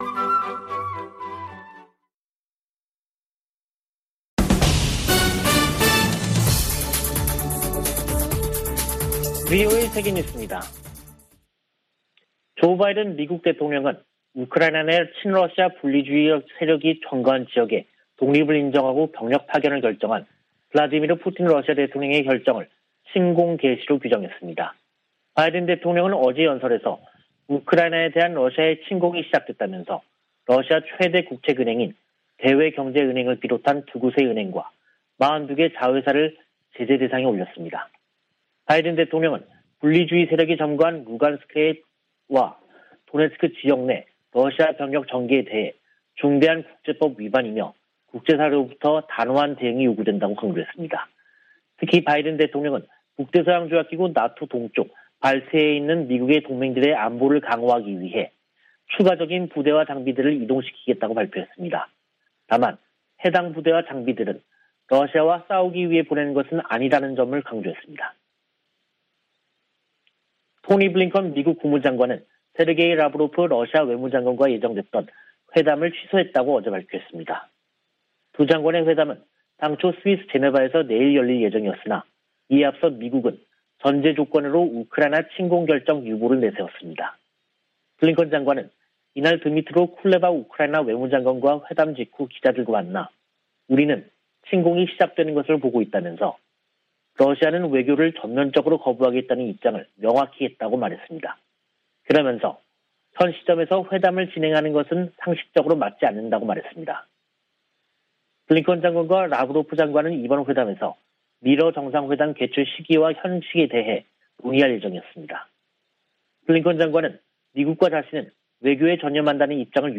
VOA 한국어 간판 뉴스 프로그램 '뉴스 투데이', 2022년 2월 23일 3부 방송입니다. 최근 미국이 B-52 전략폭격기를 괌에 전개한 것은 인도태평양 역내 공격 억지를 위한 것이라고 기지 당국자가 밝혔습니다. 미국과 일본이 탄도미사일 방어에 초점을 둔 연례 연합훈련에 돌입했습니다. 한국인 70% 이상이 자체 핵무기 개발을 지지하는 것으로 나타났습니다.